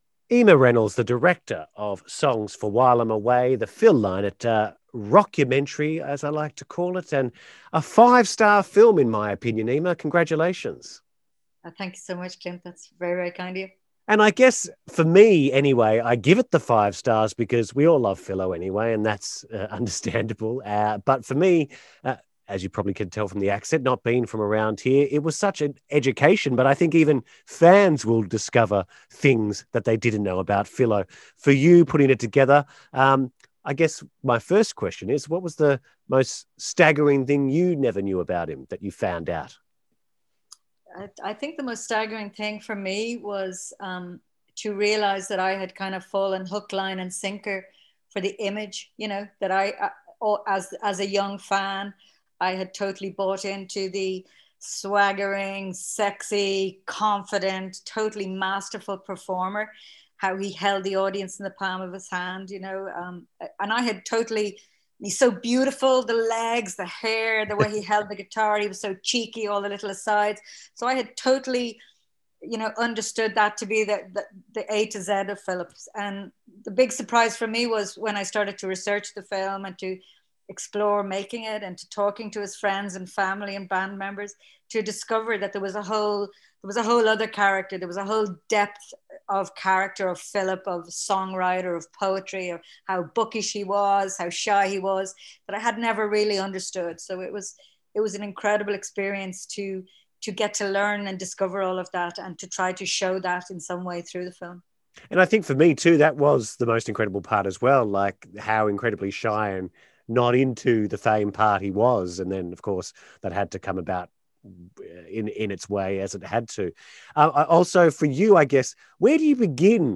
chat